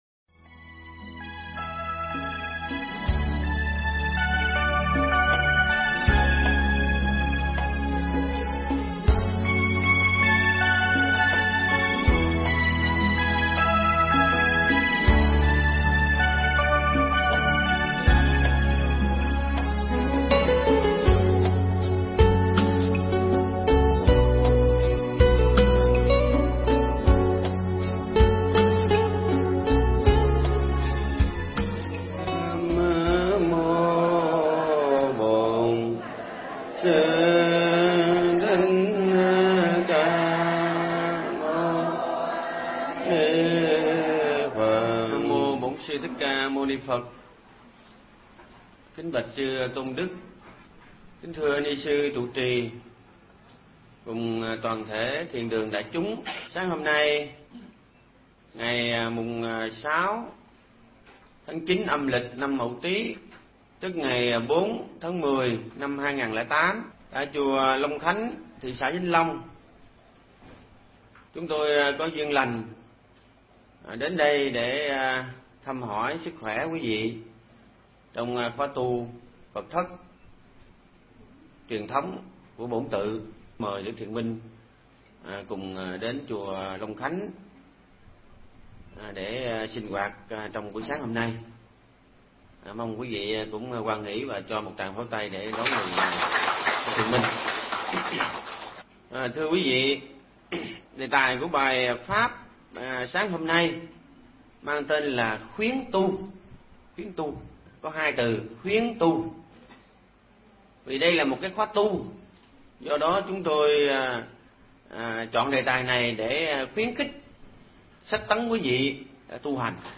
Nghe Mp3 thuyết pháp Khuyến Tu Giảng Tại Chùa Long Thành